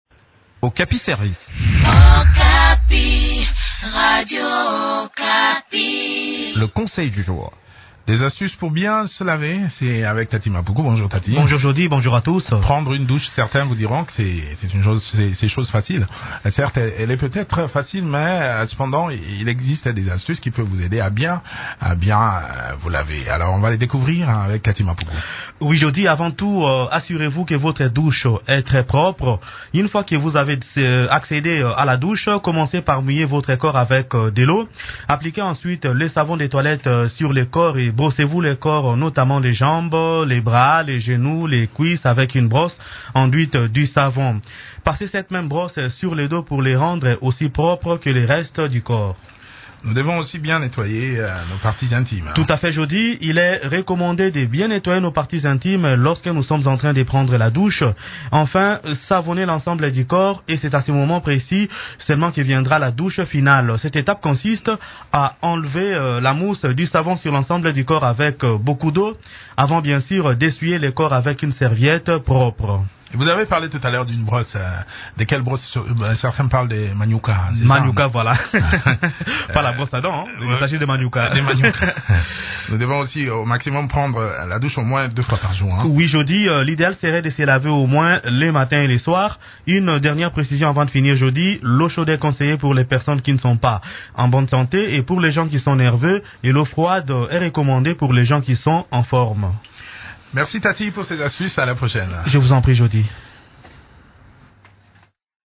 Découvrez ces astuces dans cette chronique